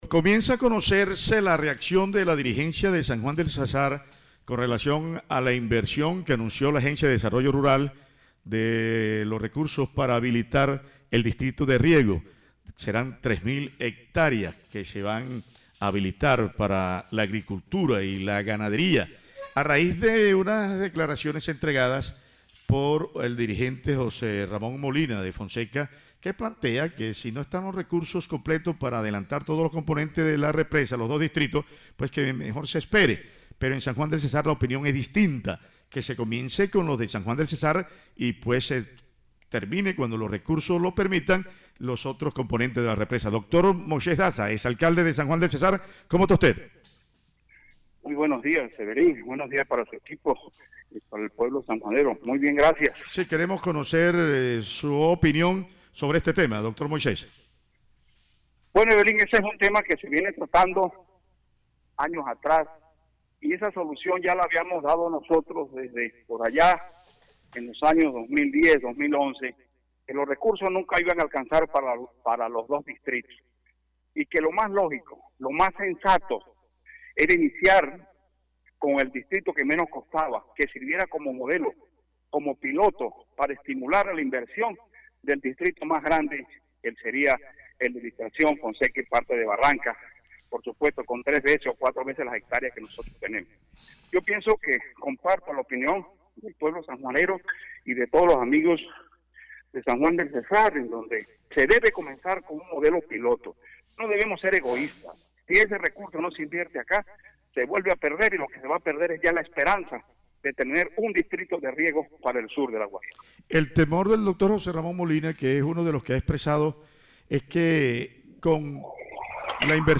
VOZ-EX-ALCALDE-MOISES-DAZA-SOBRE-DISTRITO-DE-RIEGO-SAN-JUAN.mp3